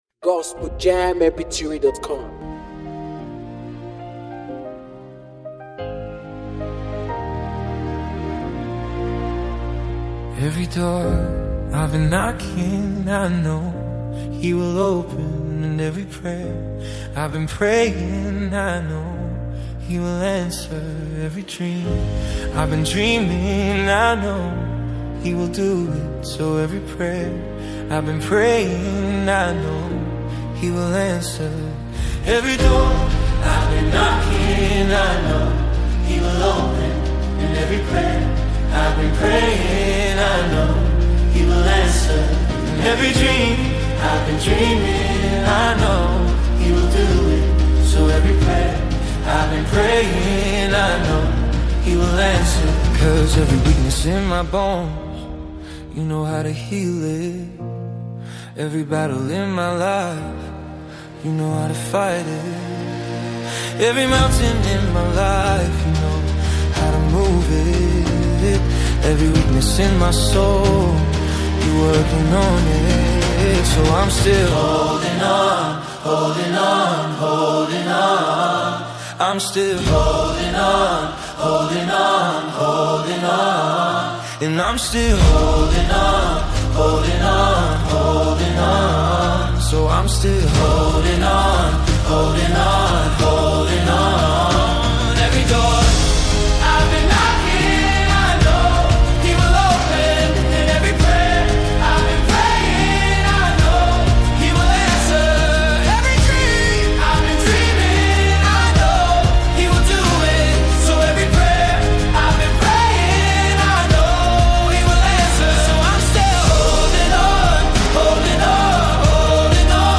musicWorship
With honest lyrics and an emotional sound